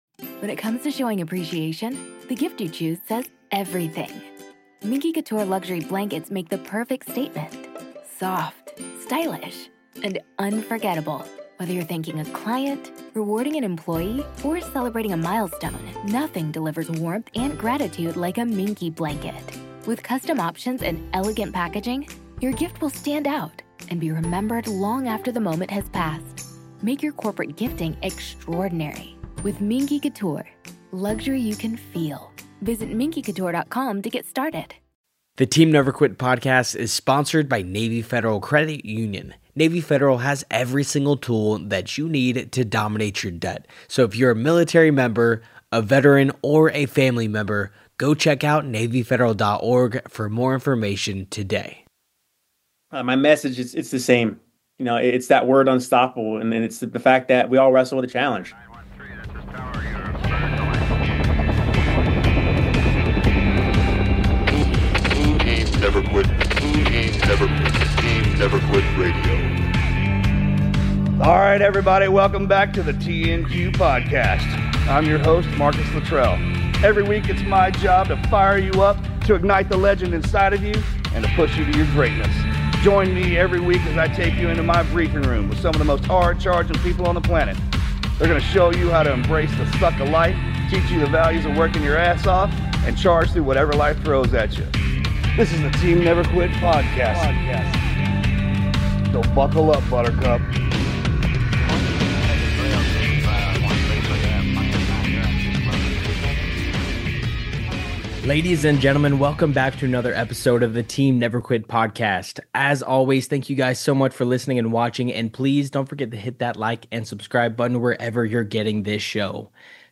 In this conversation, Anthony shares the mindset that propelled him to the top of collegiate wrestling as well as lessons on perseverance, faith, and unlocking hidden potential.